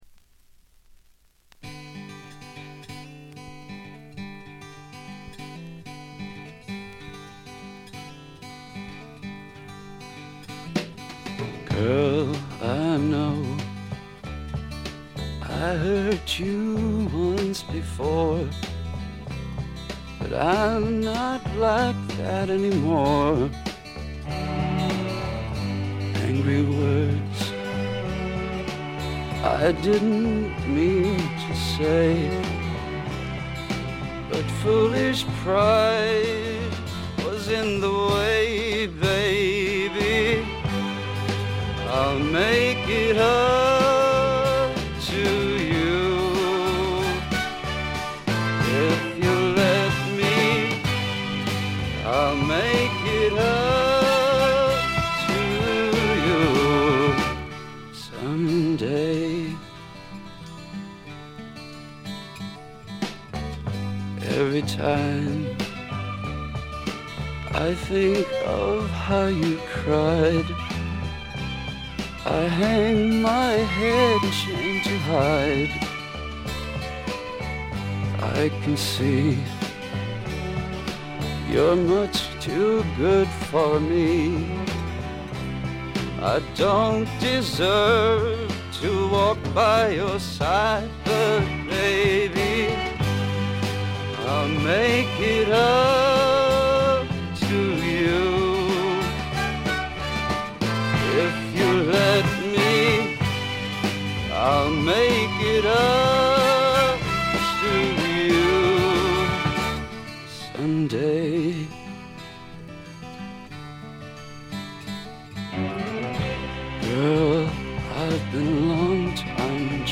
ところどころでチリプチ。
すべて自作曲で独特のヴォーカルもしっかりとした存在感があります。
試聴曲は現品からの取り込み音源です。